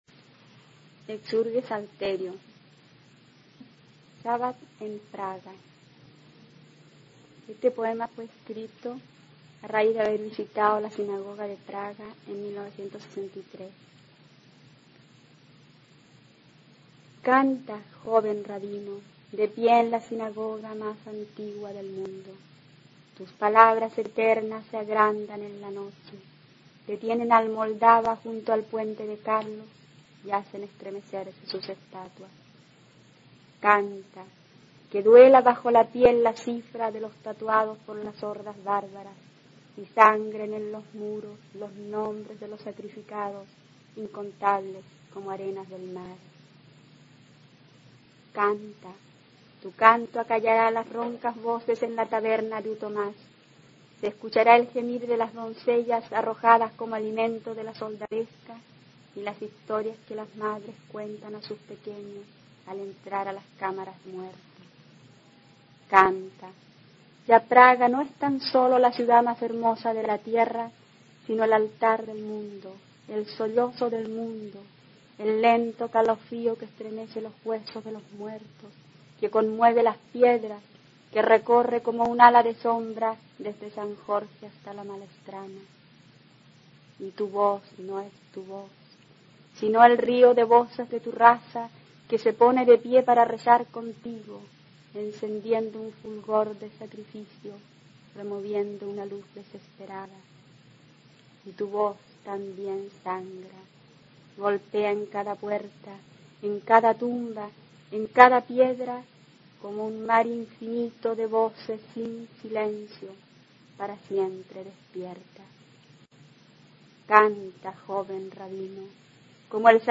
Poema